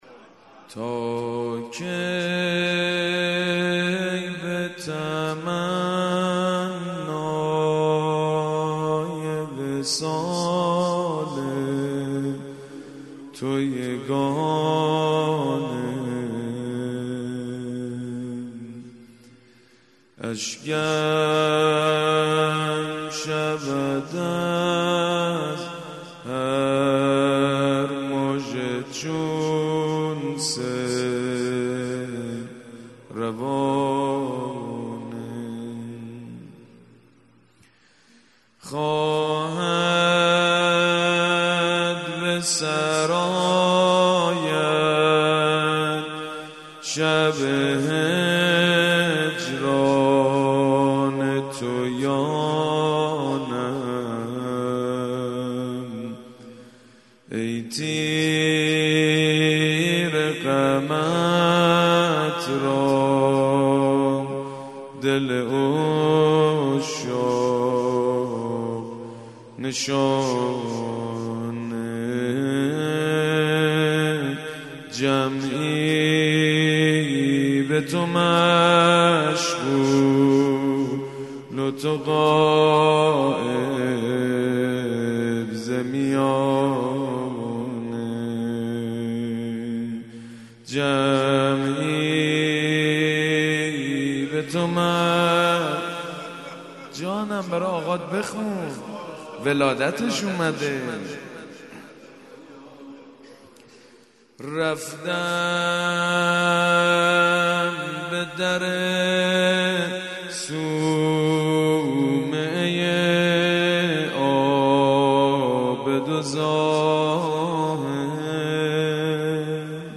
غزل و توسل : دانلود مناجات شعبانیه : دانلود